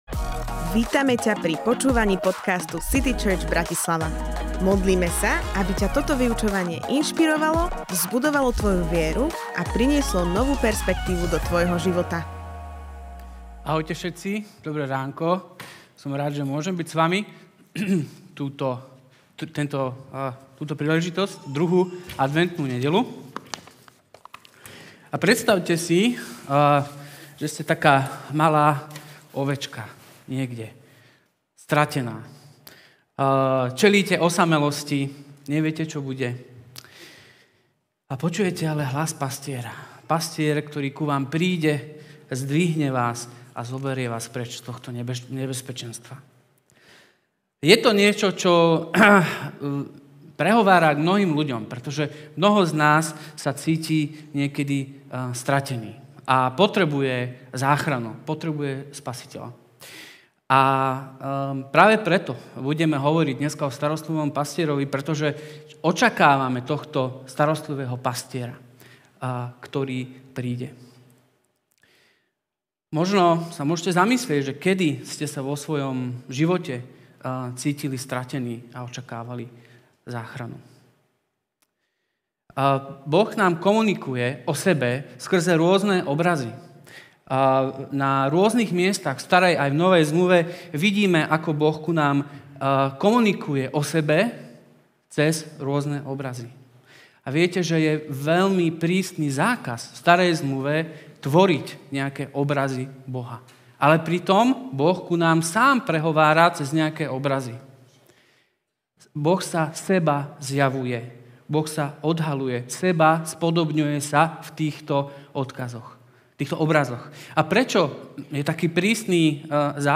Starostlivý pastier Kázeň týždňa Zo série kázní